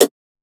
xgillclank.wav